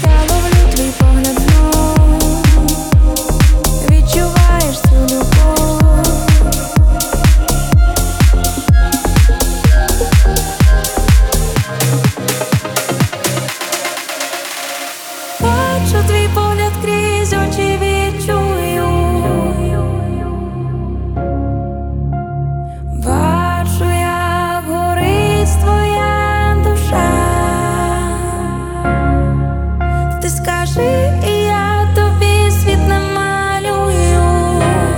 Techno Dance
Жанр: Танцевальные / Техно / Украинские